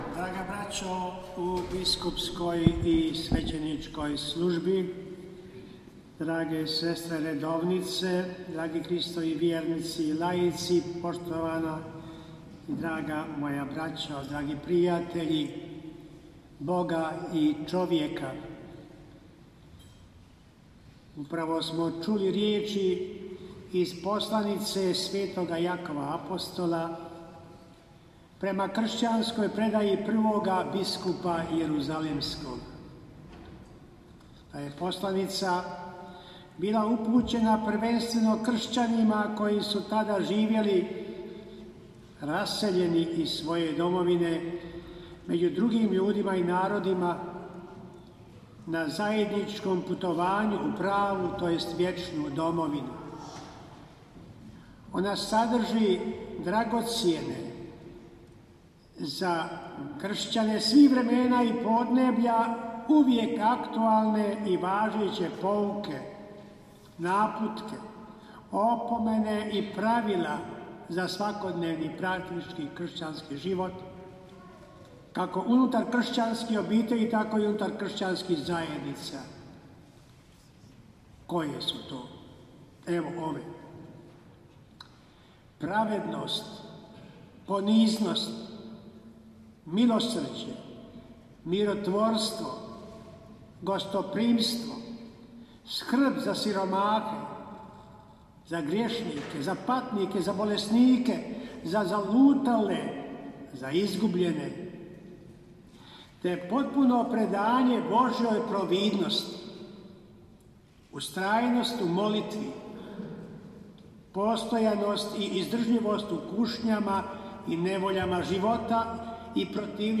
AUDIO: NAGOVOR BISKUPA KOMARICE TIJEKOM SVEČANE VEČERNJE UOČI USTOLIČENJA NADBISKUPA VUKŠIĆA
Večernju je predvodio nadbiskup Vukšić. Nakon pročitanog odlomka iz Poslanice sv. Jakova apostola(Jak 5,16. 19-20) prigodni nagovor uputio je biskup banjolučki mons. Franjo Komarica.